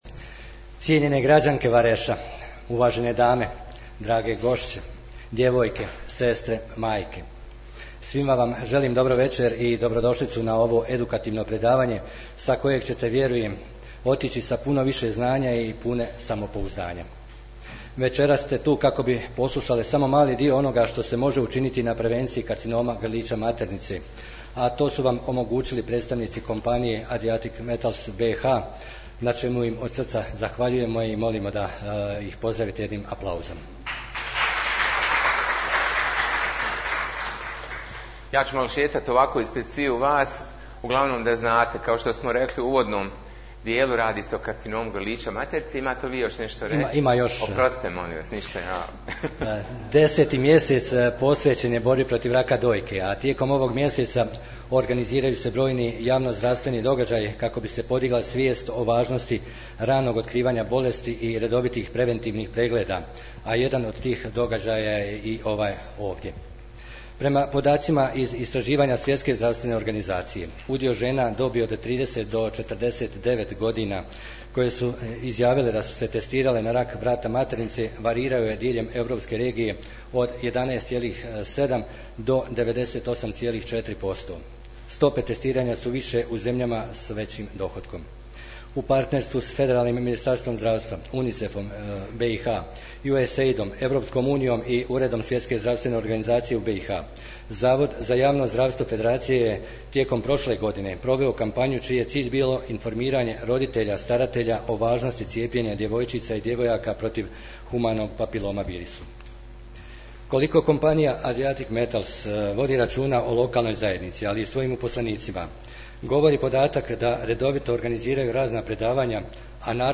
Održano edukativno predavanje o prevenciji karcinoma grlića maternice
Zahvaljujući kompaniji Adriatci Metals BH u dvorani Općinskog vijeća Vareš u četvrtak, 24.10.2024. godine održano je predavanje o prevenciji karcinoma grlića maternice.